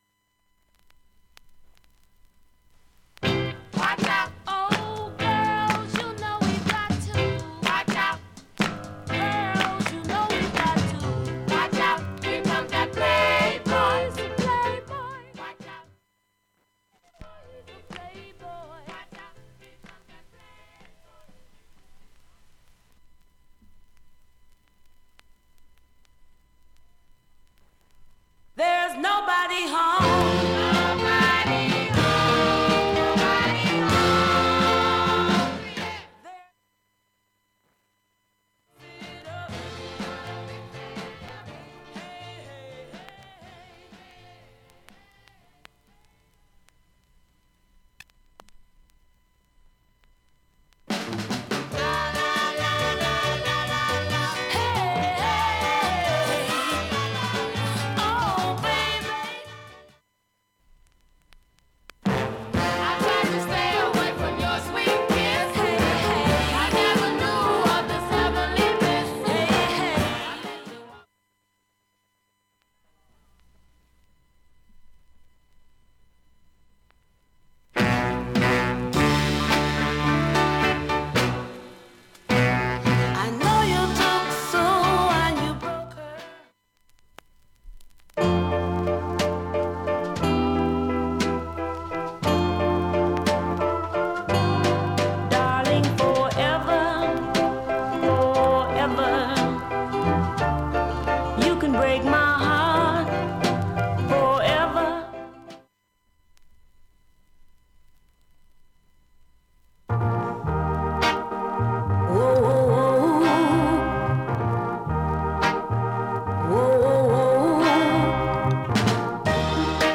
SOUL、FUNK、JAZZのオリジナルアナログ盤専門店
MONO
ノーザン・ソウル・クラシックス